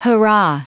Prononciation du mot hurrah en anglais (fichier audio)
Prononciation du mot : hurrah